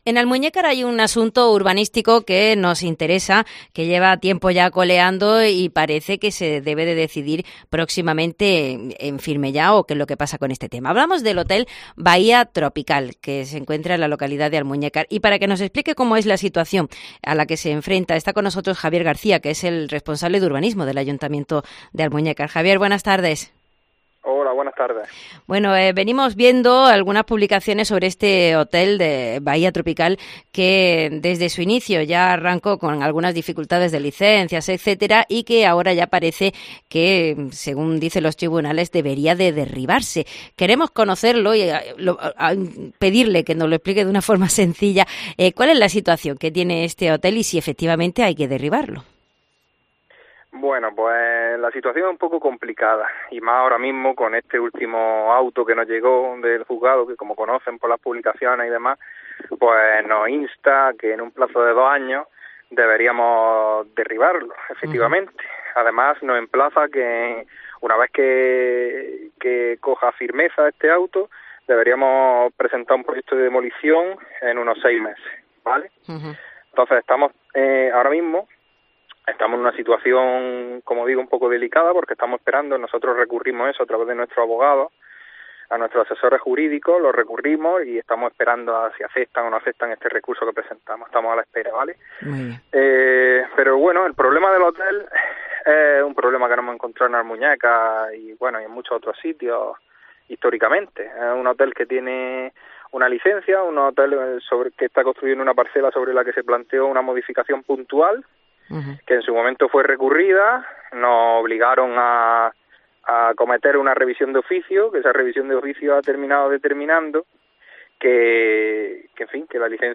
El concejal de urbanismo nos explica la situación complicada a la que se enfrenta el Ayuntamiento